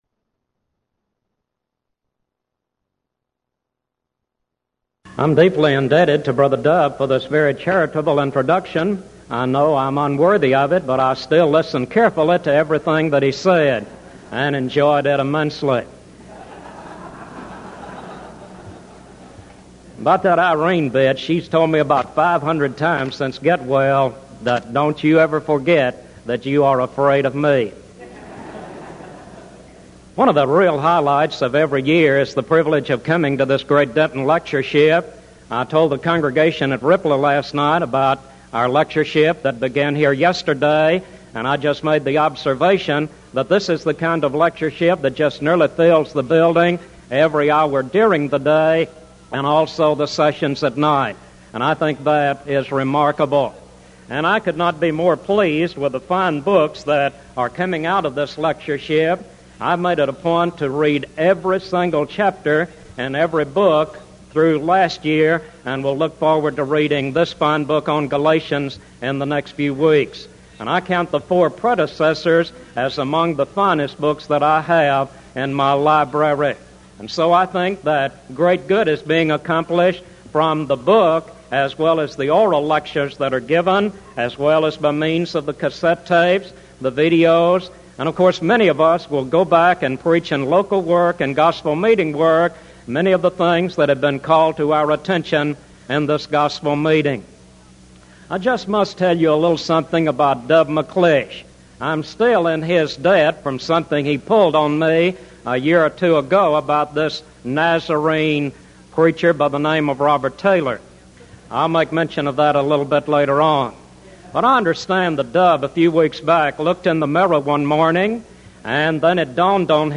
Event: 1986 Denton Lectures Theme/Title: Studies in Galatians